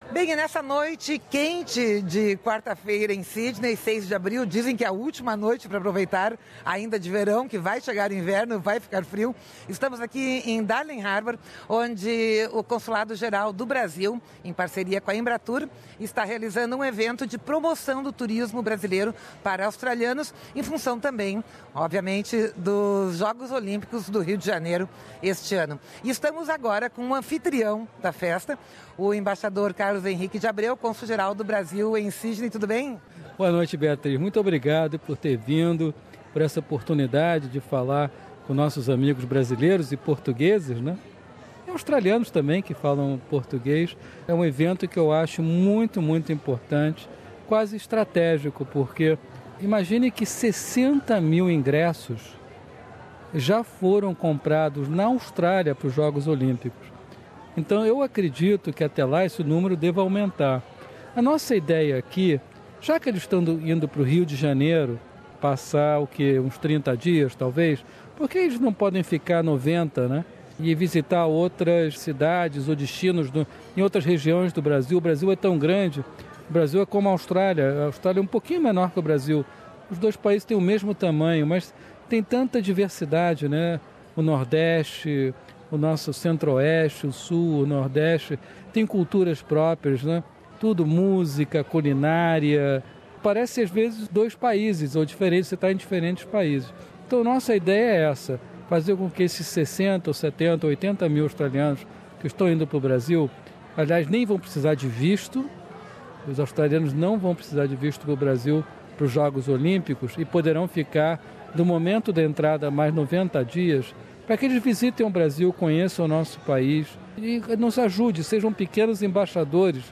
O Cônsul-Geral do Brasil em Sydney, embaixador Carlos Henrique de Abreu, em entrevista à Rádio SBS, fala da importância de promover o turismo no Brasil e dá dicas aos turistas de como se proteger do vírus Zika.